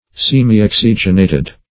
Search Result for " semioxygenated" : The Collaborative International Dictionary of English v.0.48: Semioxygenated \Sem`i*ox"y*gen*a`ted\, a. Combined with oxygen only in part.